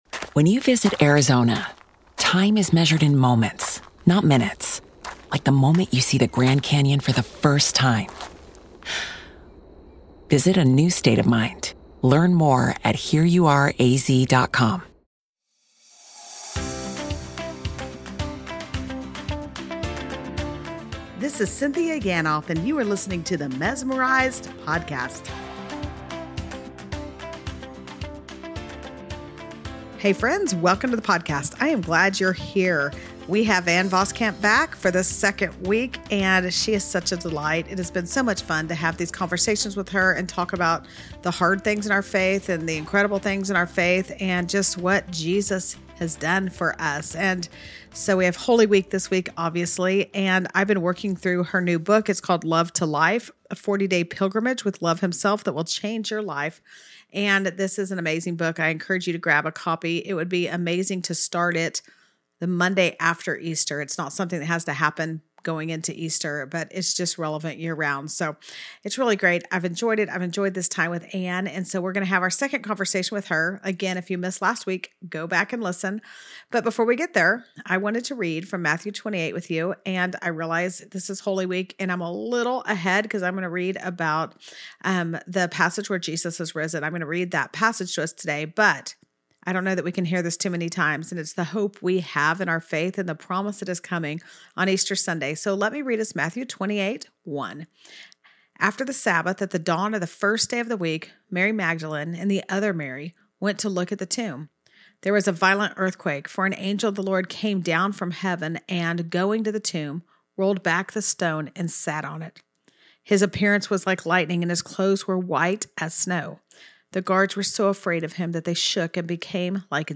Each week you’ll hear funny, authentic, and vulnerable conversations around everyday life, parenting, and following Jesus. A variety of guests will challenge us to pursue a life filled with the things that really do matter.